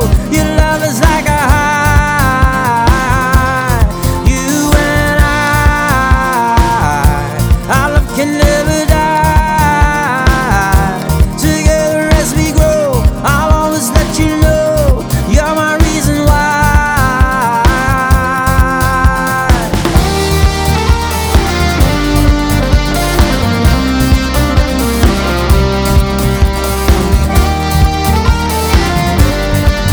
• Folk